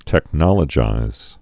(tĕk-nŏlə-jīz)